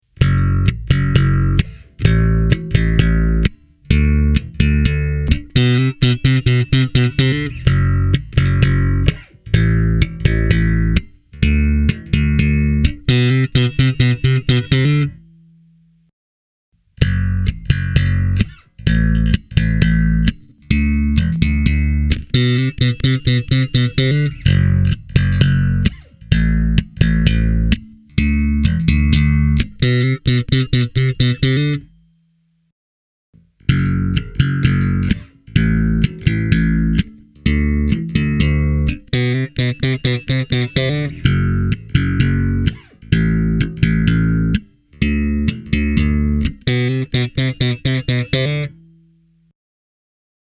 I recorded the following soundbites direct, using a Sans Amp Bass Driver DI plugged into a Focusrite Saffire 6 USB -soundcard. Each style has been recorded with one set of Sans Amp settings, taking care to keep the differences between each bass in terms of output level and sound intact.
Kataja 60 P – slap
As you can clearly hear, the Kataja-basses deliver great, vintage tones.